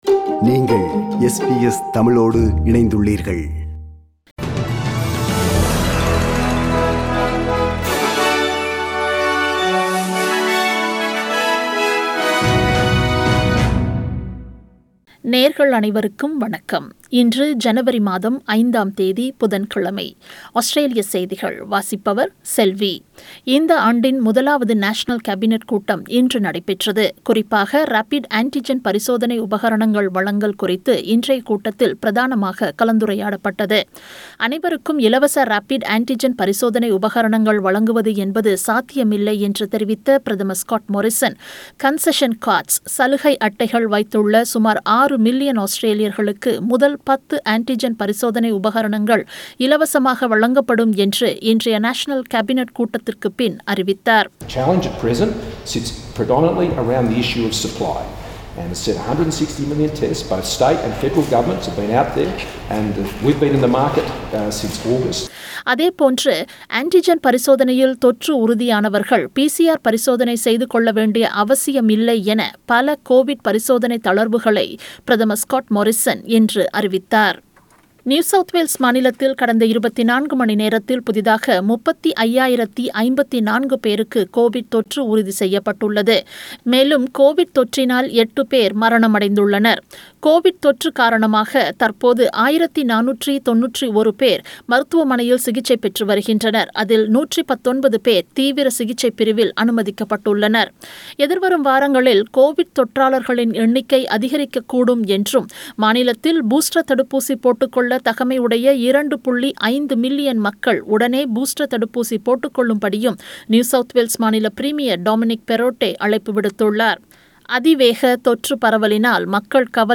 Australian News: 05 January 2022 – Wednesday